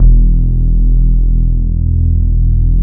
org_bass.wav